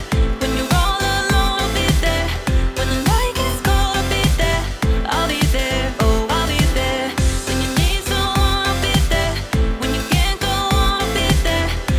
I used 8x oversampling for all examples and left the output gain control untouched in all examples for comparison purposes.
AI Loudener (85% Transparent, 15% Warm) Drive 75% resulted in -13.3 LUFS